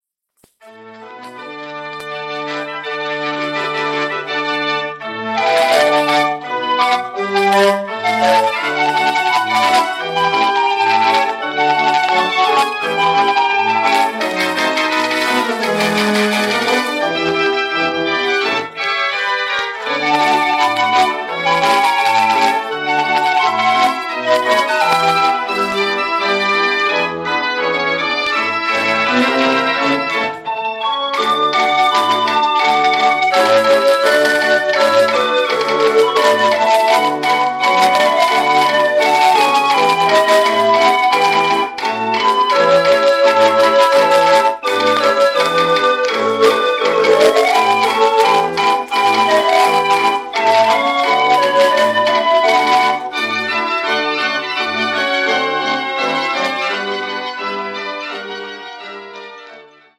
Formaat 78-toerenplaat
met een eigen mechanische klank die direct herkenbaar is